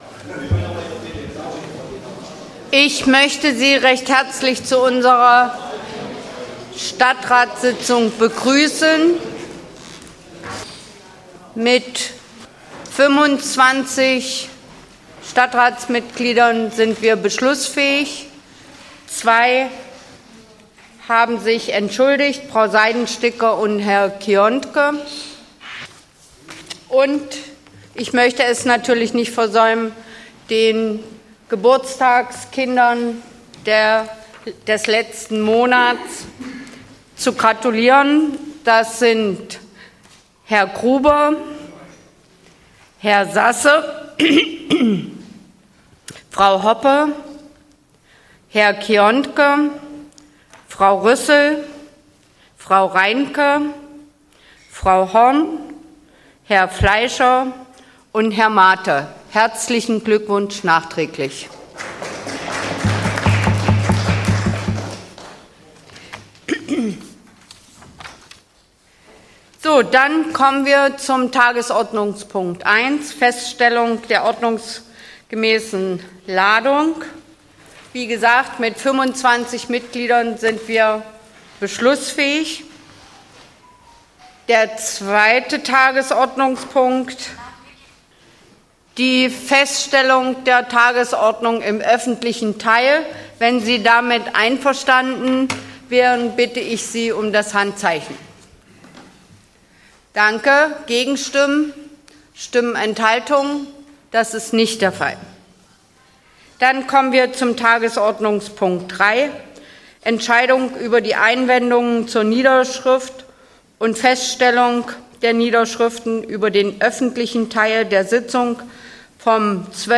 Stadtratssitzung in Aschersleben
Der Ascherslebener Stadtrat hat wieder im Bestehornhaus getagt. Diesmal ging es unter anderem um eine Änderung der Betriebssatzung für den Bauwirtschaftshof, um die Wahl der Schöffen für die Amtsperiode ab 2024 und um die Baupläne für das neue Amtsgericht an der Darre.